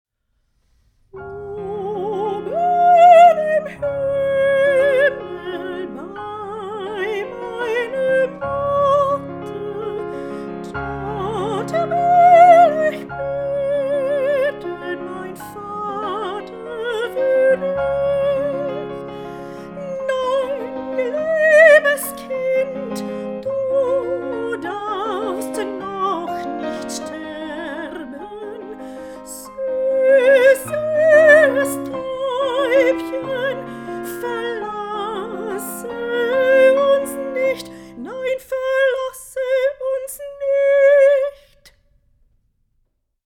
Nein, liebes Kind (Chor)
13_nein_liebes_kind_chor.mp3